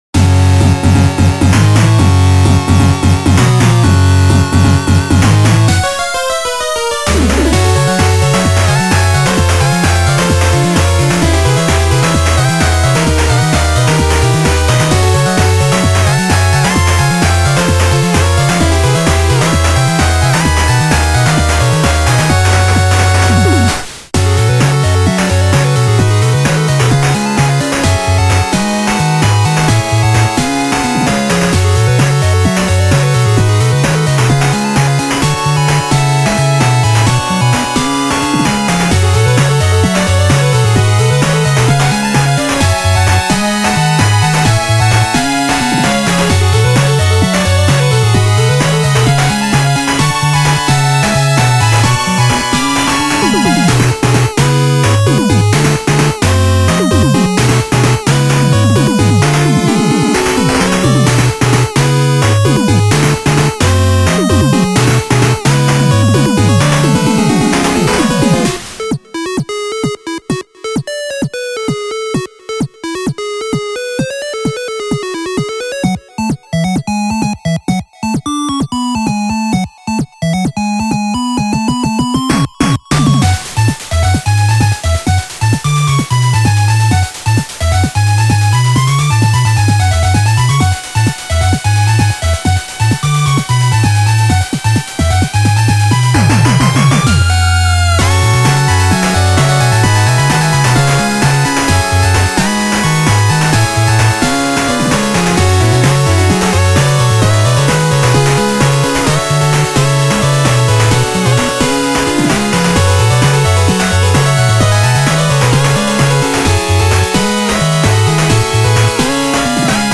ジャンルファミコン風
使用例ボス戦闘、絶望、負けイベント
BPM１３０
使用楽器8-Bit音源